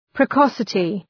Προφορά
{prı’kɒsətı}